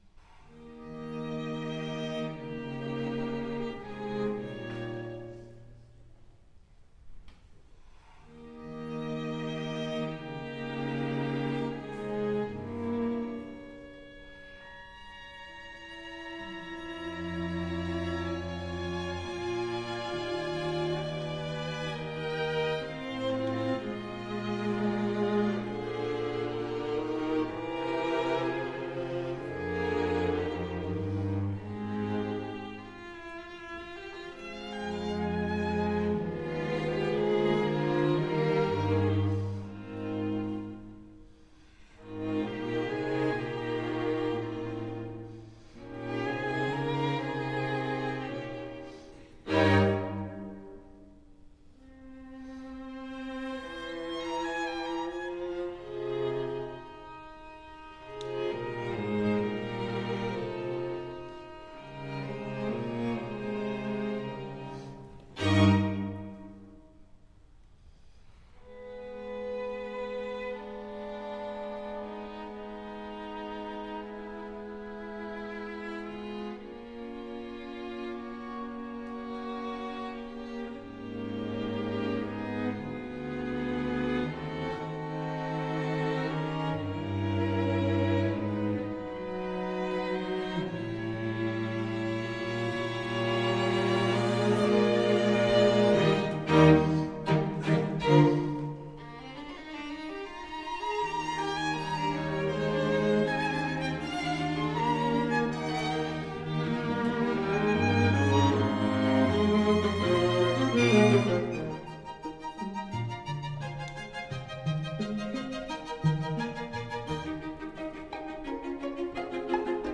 Instrument: String Quartet
Style: Classical
Audio: Boston - Isabella Stewart Gardner Museum.
Attribution: Audio: Borromeo String Quartet (String Quartet).
string-quartet-10-op-74.mp3